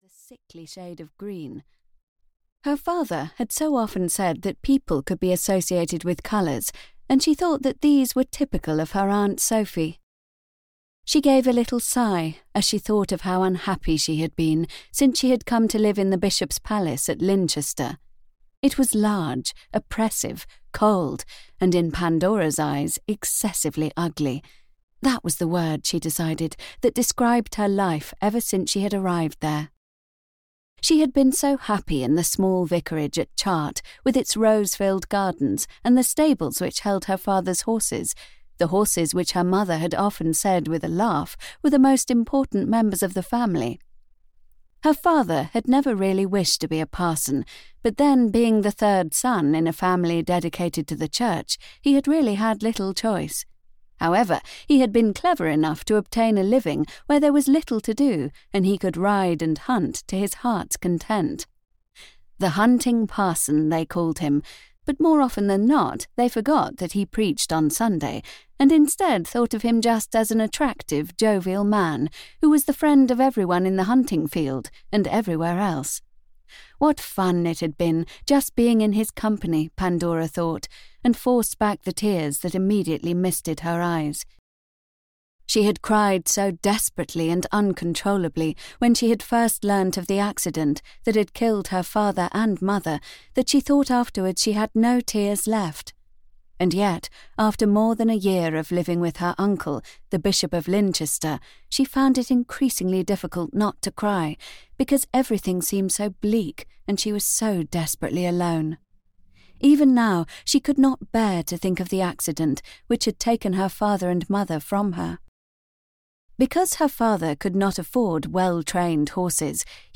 The Saint and the Sinner (EN) audiokniha
Ukázka z knihy